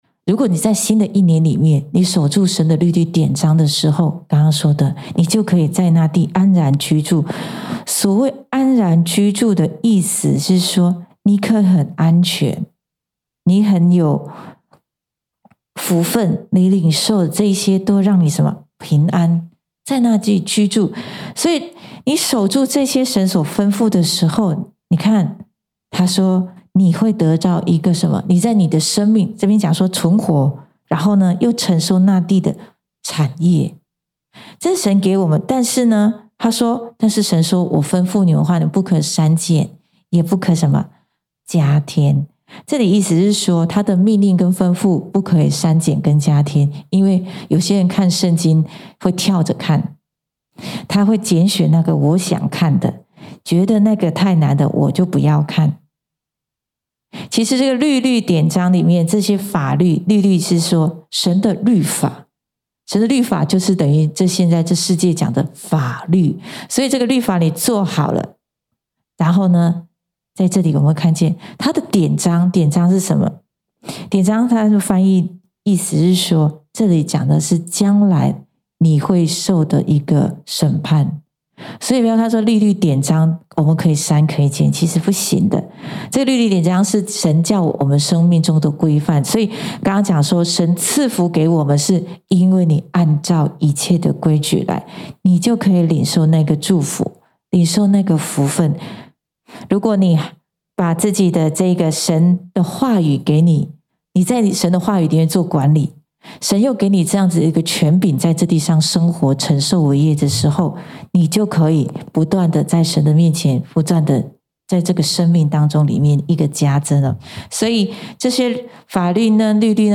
in 主日信息 〝他們要在這地上安然居住。